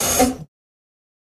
Fountains
Water Fountain Off